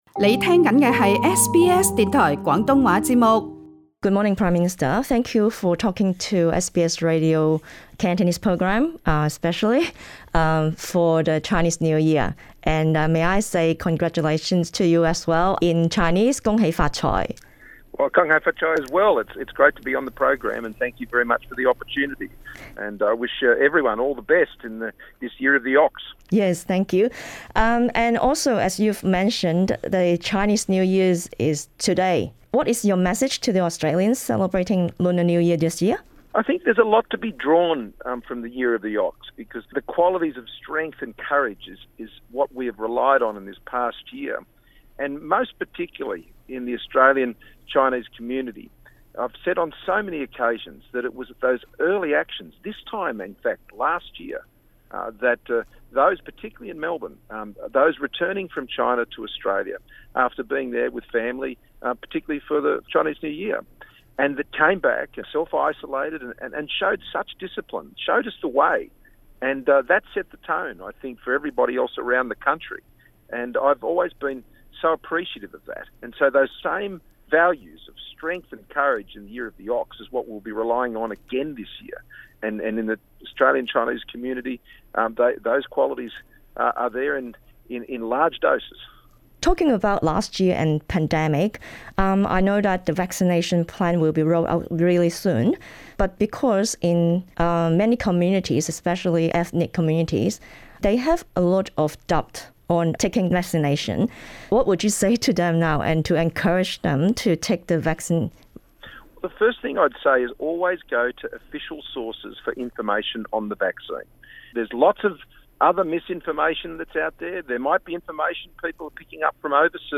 【农历新年】专访澳洲总理莫理逊
农历新年前夕，澳洲总理莫理逊（Scott Morrison）接受 SBS 广东话节目专访，向国内华裔社群拜年，同时亦谈及澳洲的新冠病毒疫情及疫苗接种计划、澳中关系、为香港人提供的签证优待计划、国民若持有双重国籍所可能失去的澳洲领事援助等话题。莫理逊同时指出，今年是辛丑牛年，呼吁国民在来年继续仿效牛的性格特质，坚毅不屈地迎接新一年。